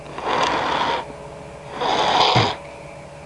Blow Nose Sound Effect
Download a high-quality blow nose sound effect.
blow-nose-1.mp3